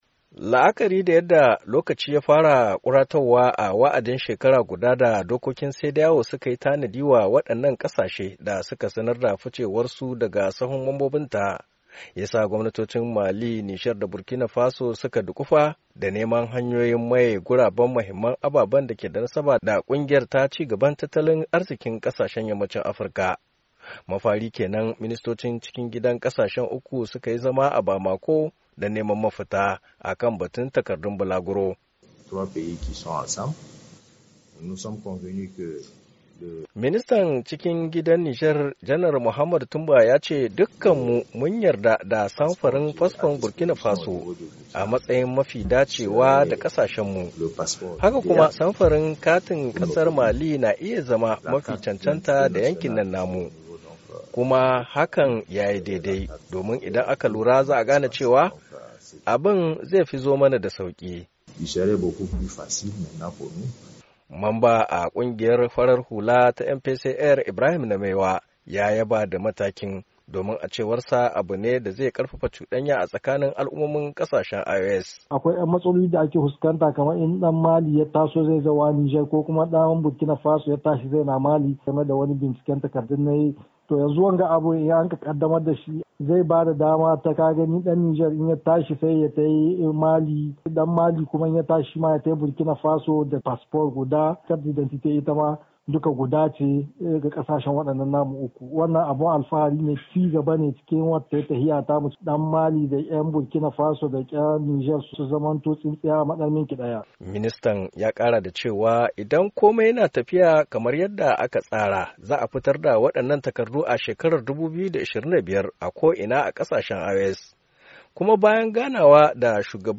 NIAMEY, NIGER —